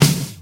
• Classic Prominent Steel Snare Drum Sample D Key 172.wav
Royality free snare sound tuned to the D note. Loudest frequency: 2296Hz
classic-prominent-steel-snare-drum-sample-d-key-172-hOA.wav